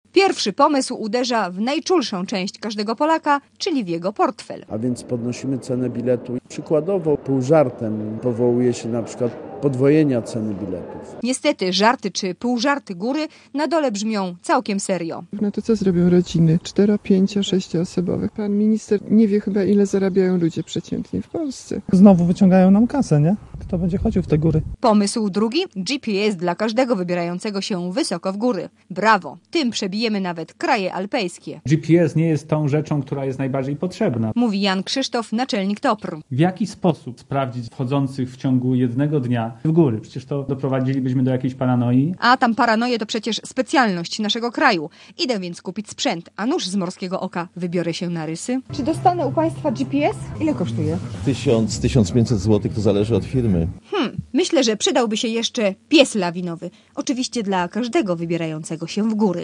**Posłuchaj relacji reportera Radia Zet (0.5 MB)